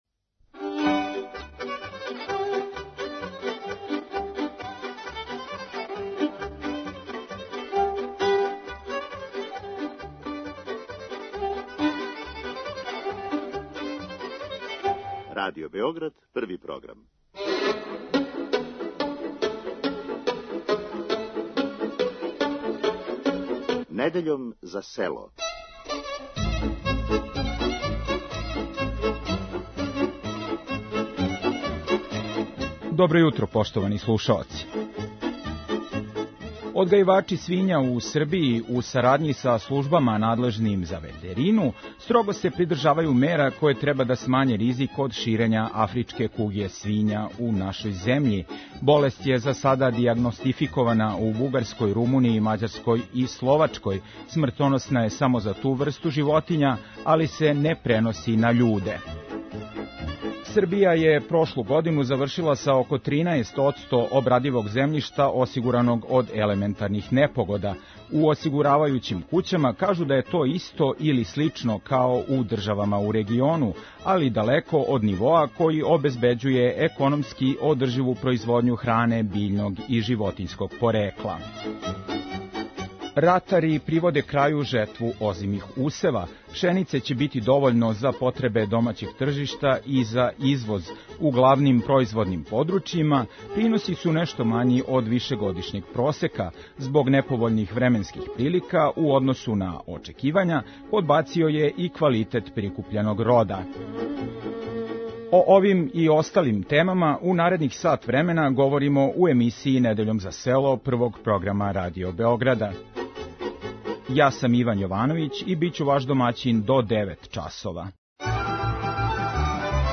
Уз остале теме из области пољопривреде у емисији Вас чека и традиционална народна музика из свих делова Србије.